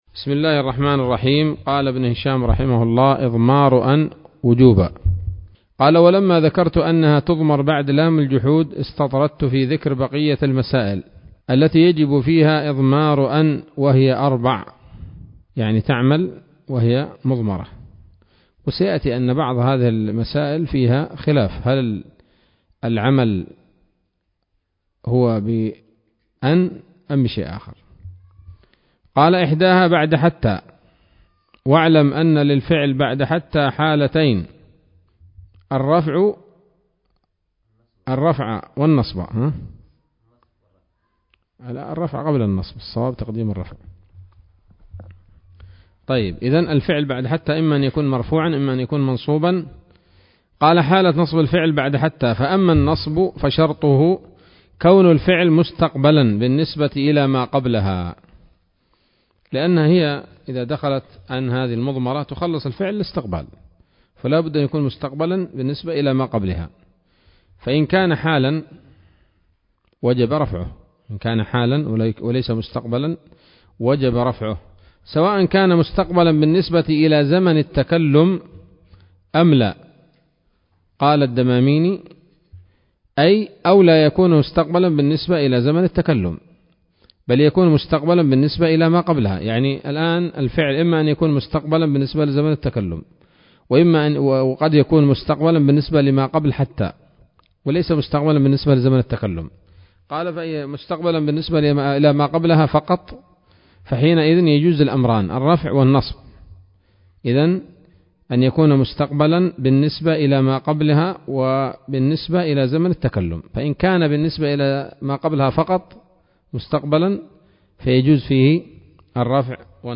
الدرس الحادي والثلاثون من شرح قطر الندى وبل الصدى [1444هـ]